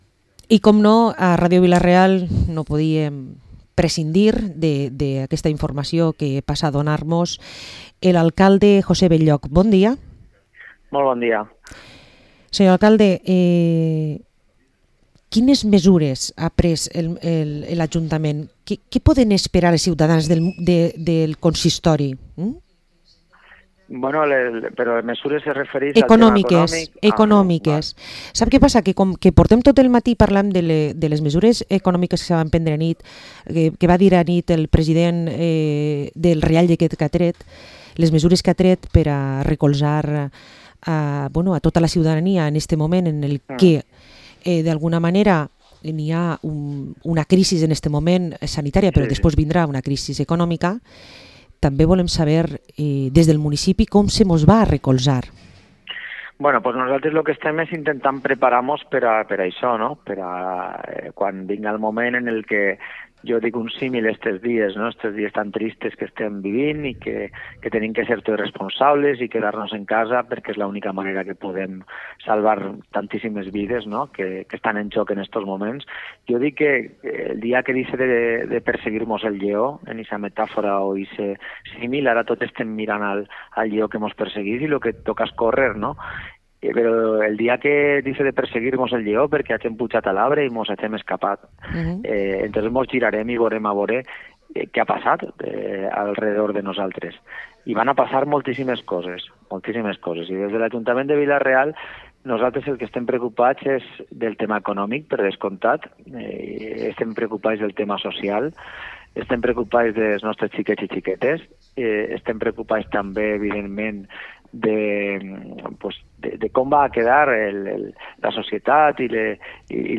Entrevista a José Benlloch, alcalde de Vila-real